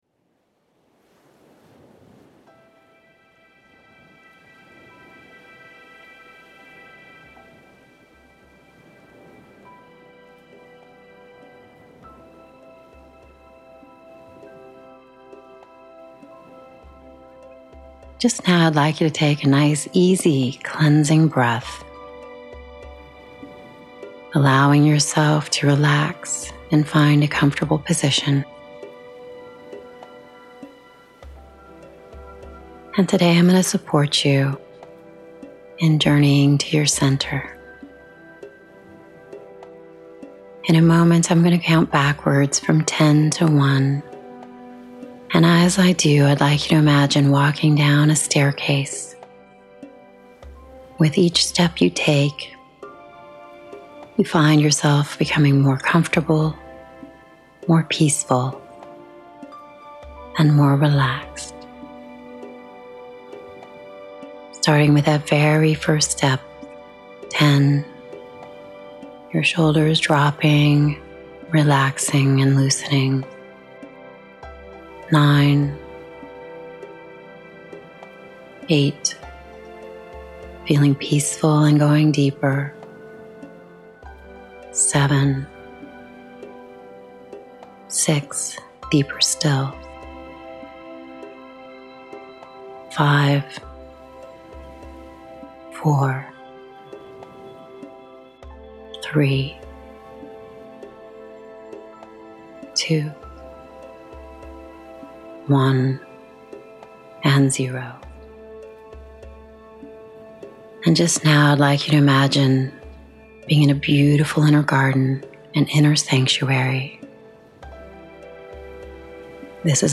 7-Minute Guided Meditation for Manifesting Prosperity